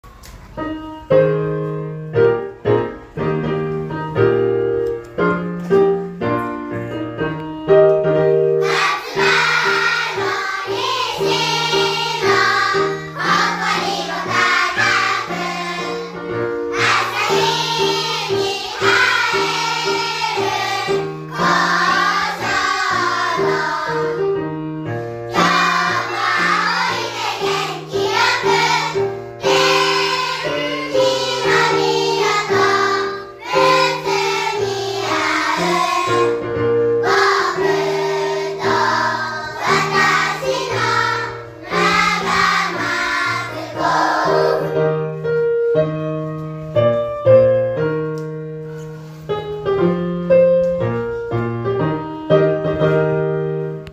♪校歌♪1年生